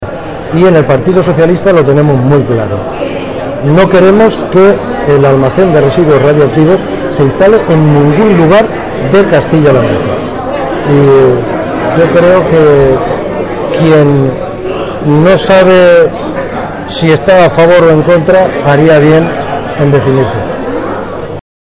Las declaraciones de Francisco Pardo se produjeron en el marco de las Conferencias Políticas 2010 de Almansa que, bajo el título «Almansa + PSOE, Trabajando por el futuro», tratarán de reflexionar acerca del presente y futuro de la localidad.
Pardo durante su intervención